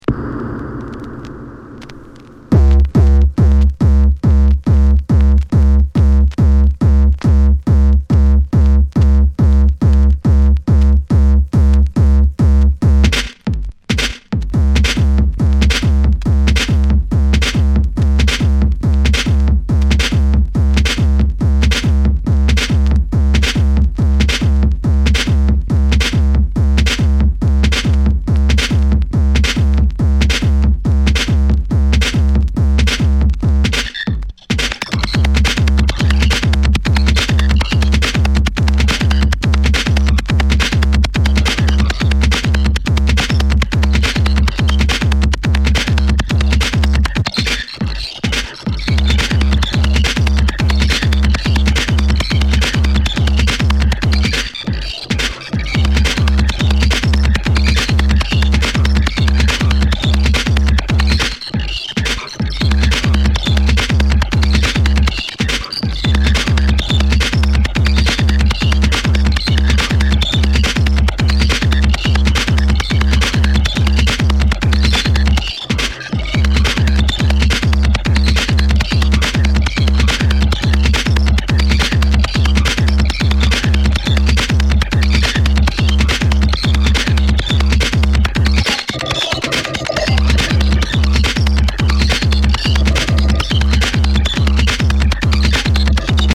ディープテクノミニマル
ベースラインと手数多めのハットが入ってきて緊張感MAXで疾走する